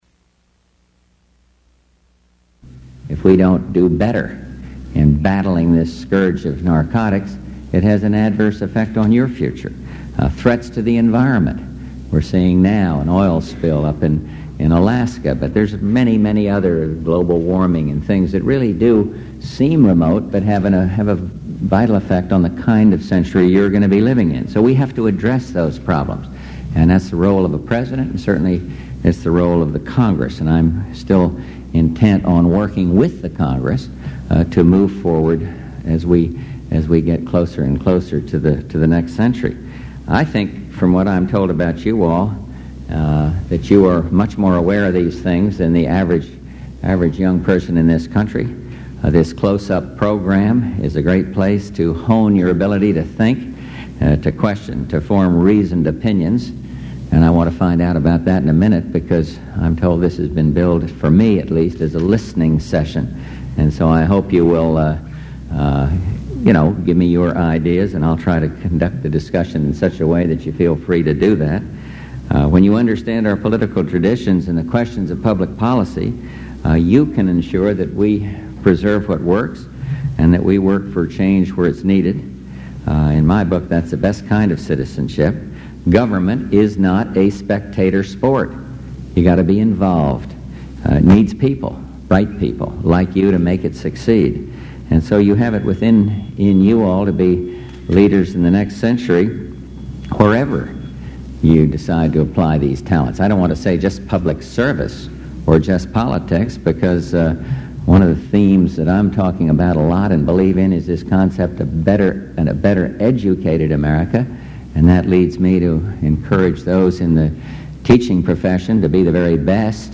George Bush speaks to high school students brought into the White House by the Close-Up Foundation, answering questions about education, drugs, the budget, decentralization, and international studies